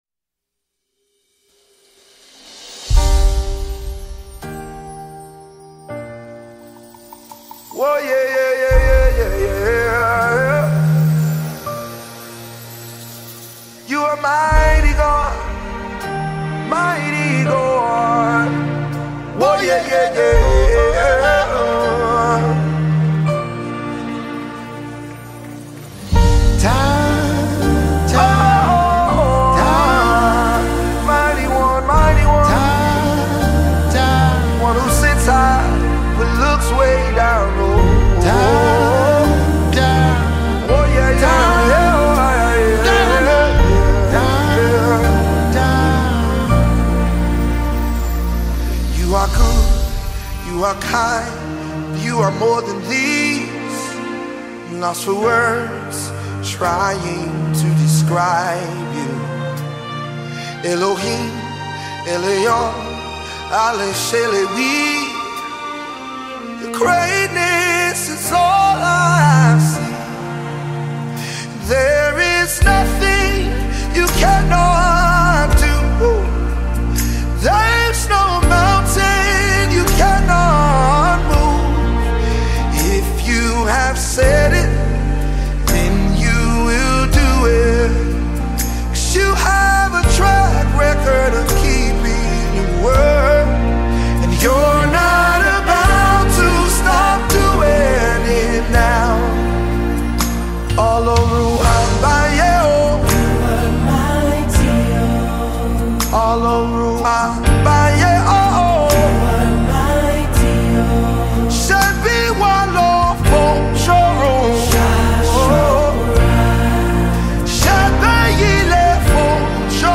Award winning Nigerian gospel music minister